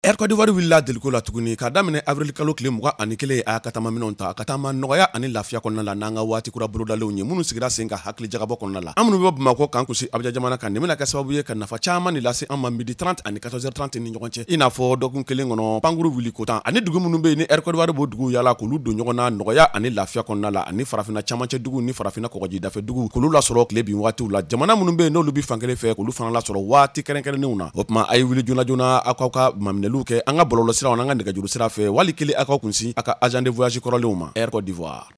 À l’occasion du lancement de son nouveau programme de vols, notre agence a réalisé un spot publicitaire radio diffusé sur les principales stations partenaires, ainsi qu'une vaste opération d’achat d’espace pour maximiser l’impact de la campagne.